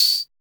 CR78 TAMB.wav